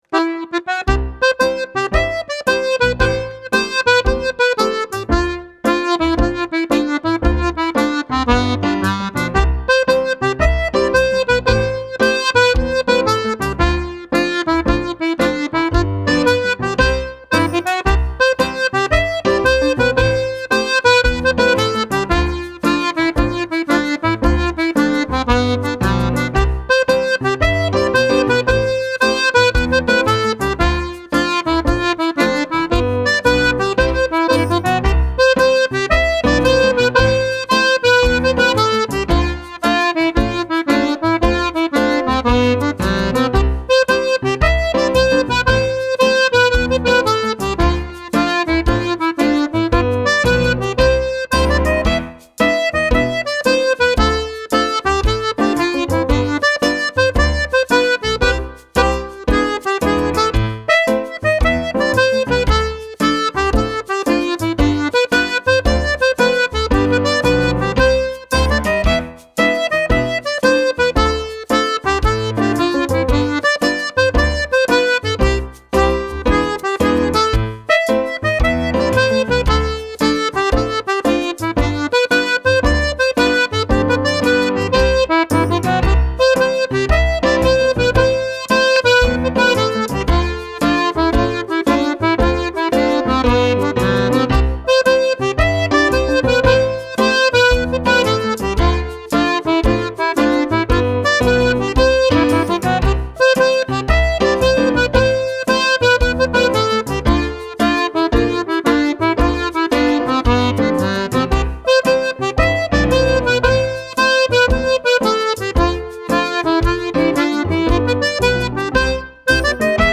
Hornpipes